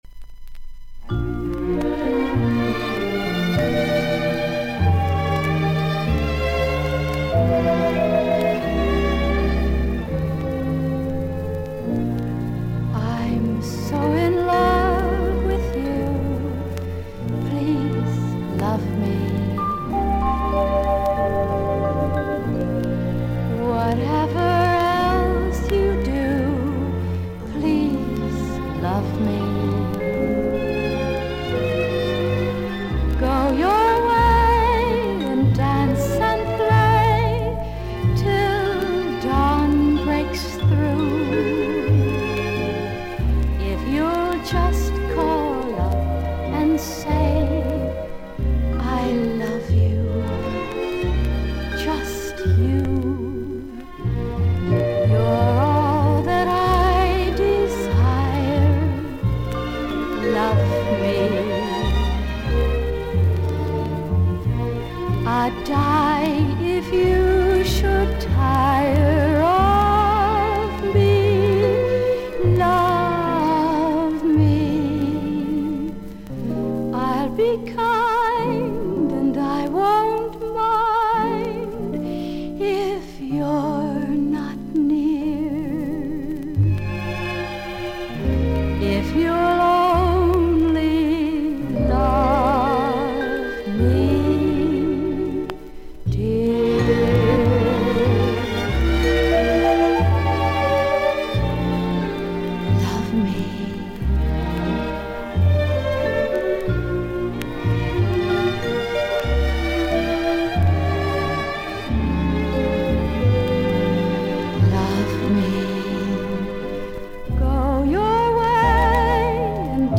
試聴はノイズが多めにあるA1〜A2序盤です。少々軽いパチノイズの箇所あり。少々サーフィス・ノイズあり。
ハスキーでセクシーな囁き声が魅力の女性シンガー。